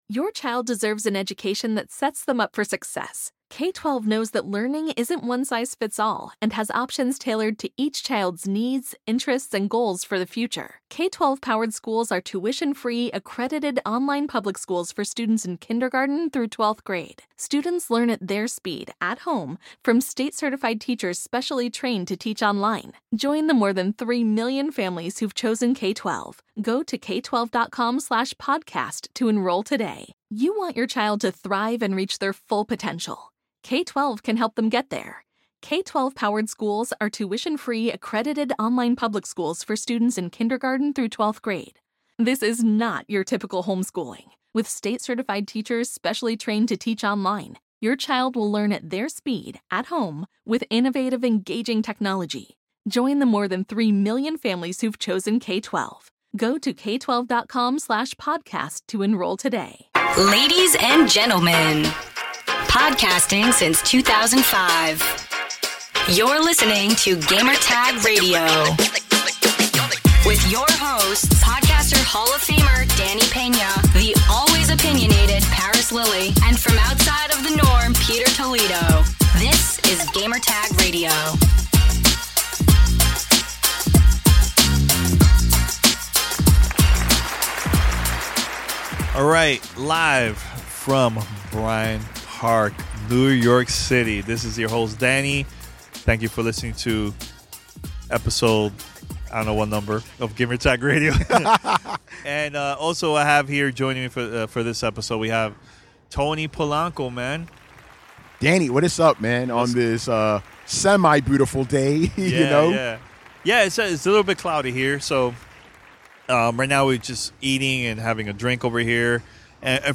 Live from Bryant Park in New York City